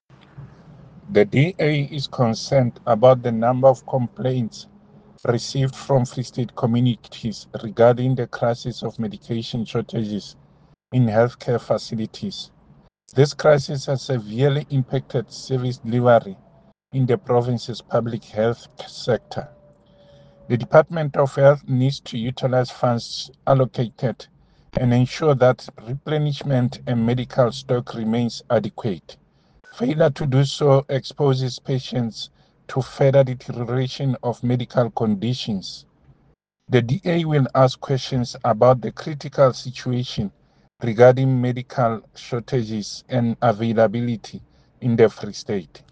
Issued by David Masoeu – DA Spokesperson for Health in the Free State Legislature
Sesotho soundbites by David Masoeu MPL and Afrikaans soundbite by Werner Pretorius MPL.